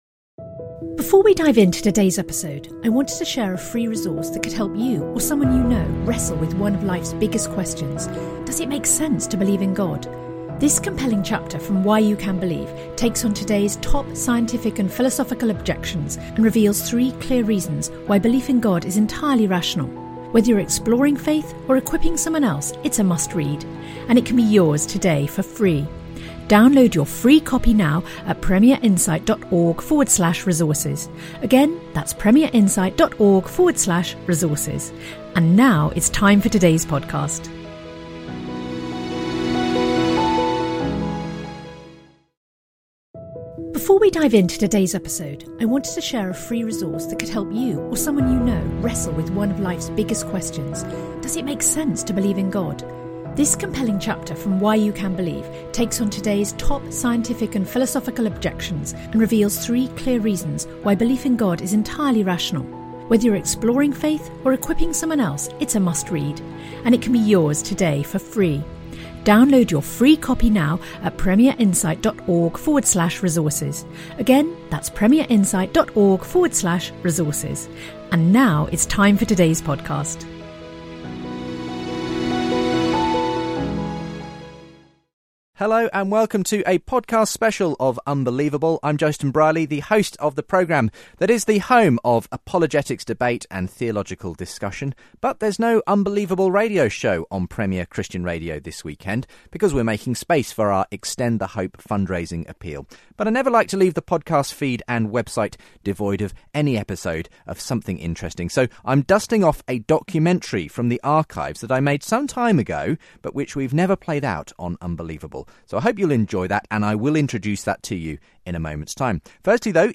radio documentary